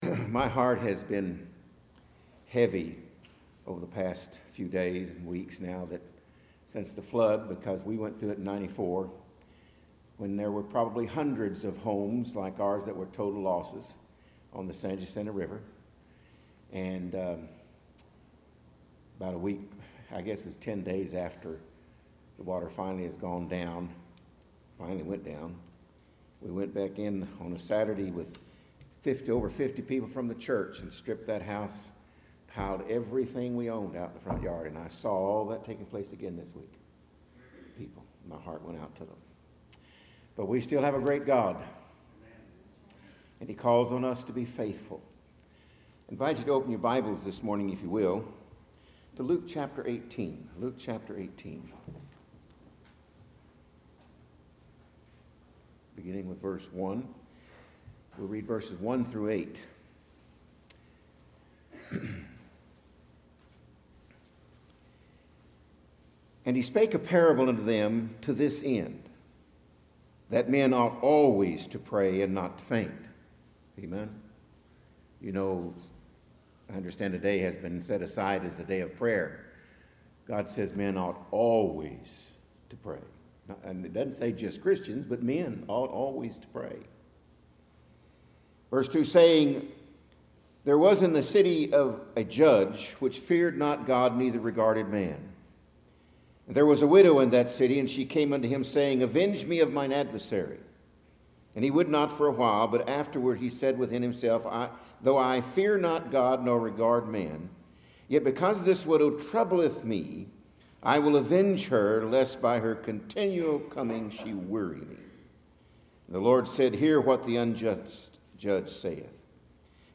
Series: Stand Alone Sermons
Luke 18:1-8 Service Type: AM Worship Bible Text